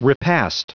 Prononciation du mot repast en anglais (fichier audio)
Prononciation du mot : repast